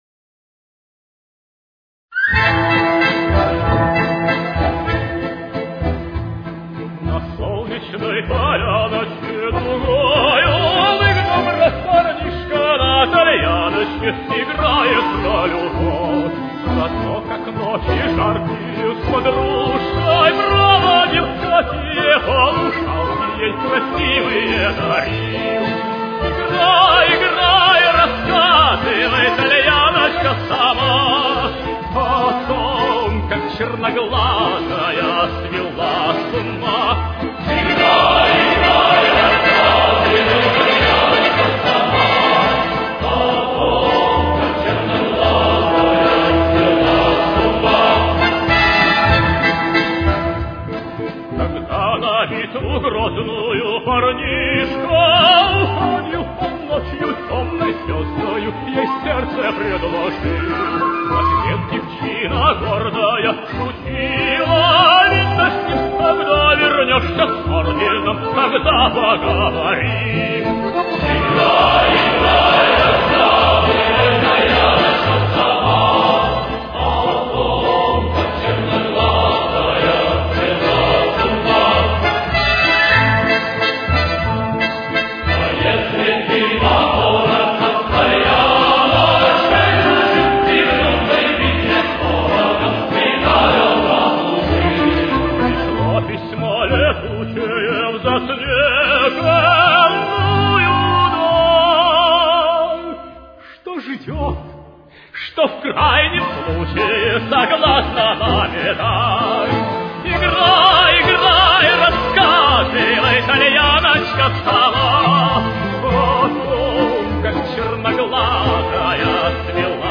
Ля минор. Темп: 105.